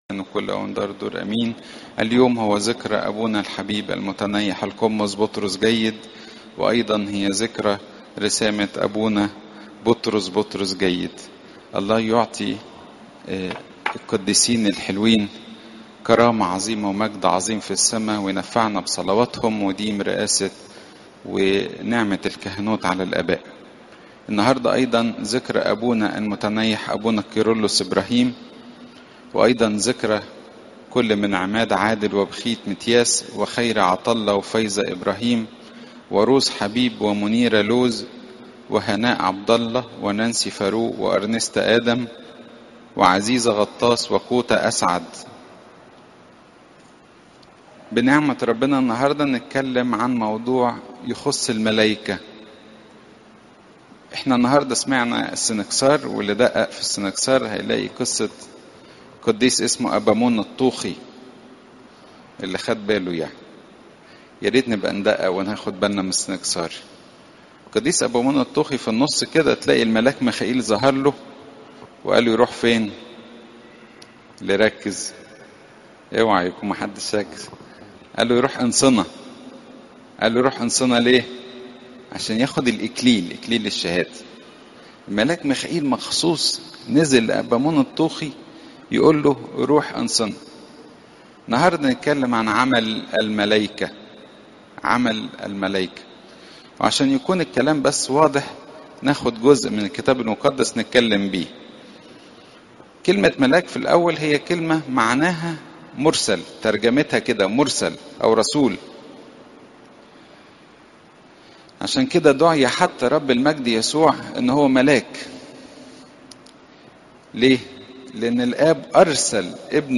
عظات قداسات الكنيسة